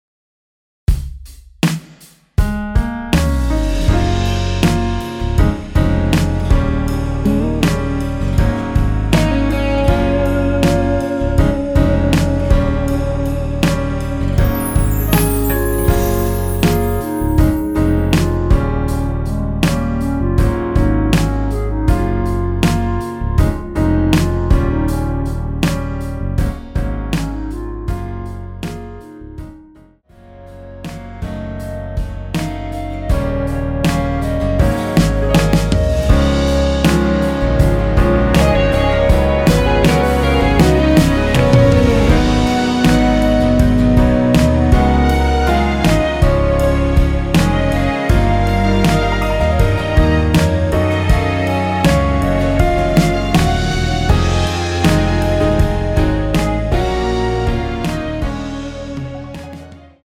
엔딩이 페이드 아웃이라 라이브 하시기 좋게 엔딩을 만들어 놓았습니다.
Db
멜로디 MR이라고 합니다.
앞부분30초, 뒷부분30초씩 편집해서 올려 드리고 있습니다.
중간에 음이 끈어지고 다시 나오는 이유는